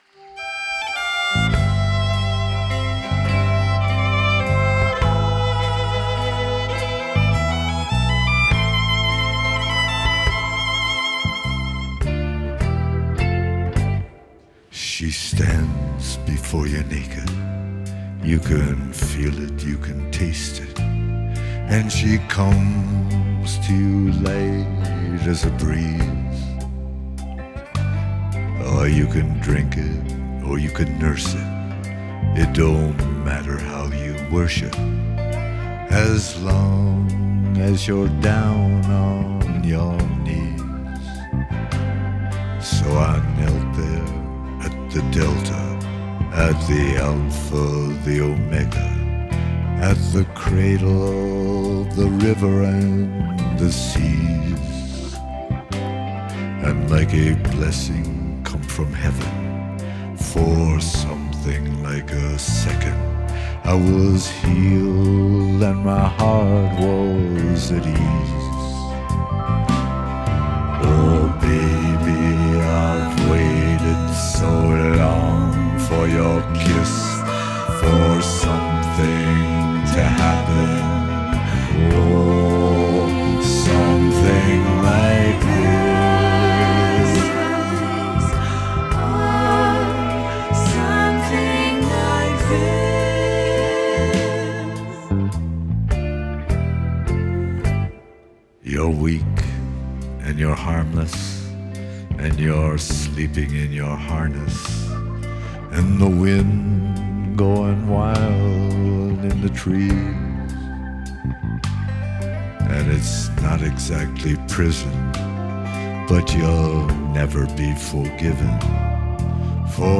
Genre: Folk, Blues, Singer-Songwriter
Live at Dublin Show, 2012